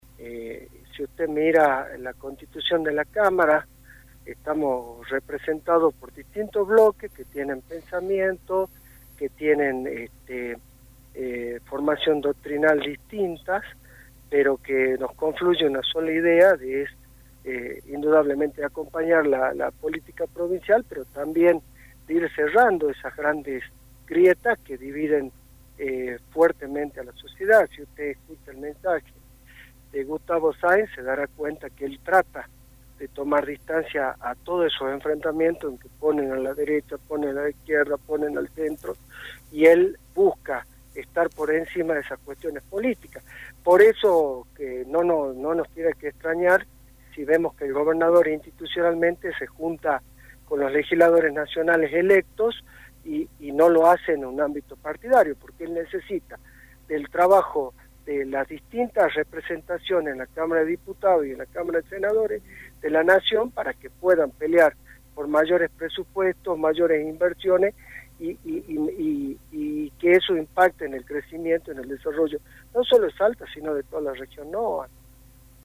SALTA (Redacción) – Germán Rallé, miembro de la Cámara de Diputados de Salta, fue entrevistado en un programa radial provincial y confirmó que el Bloque Justicialista estará compuesto por 28 diputados salteños, con una gran pluralidad representativa y de los legisladores en si mismo.